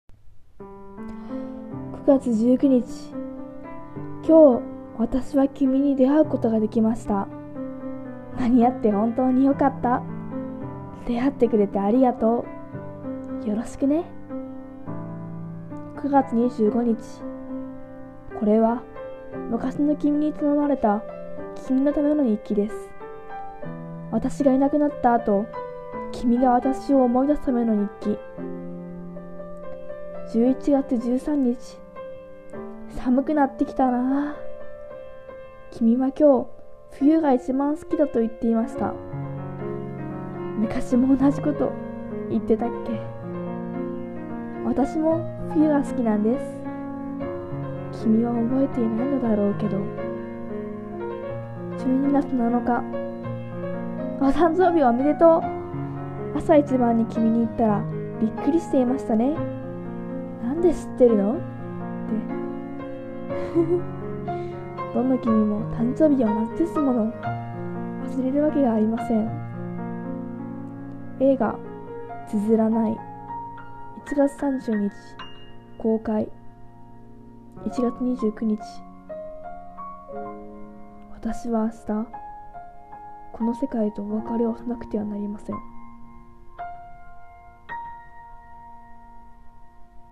映画予告風朗読台本「綴らない」